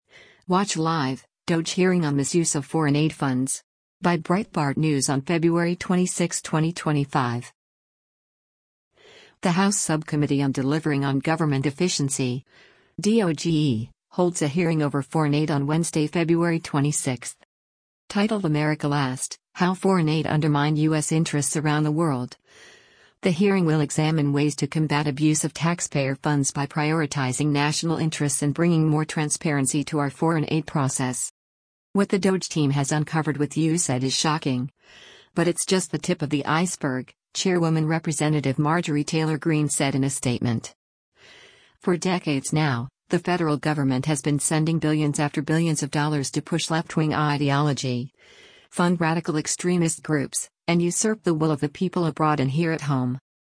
The House Subcommittee on Delivering on Government Efficiency (DOGE) holds a hearing over foreign aid on Wednesday, February 26.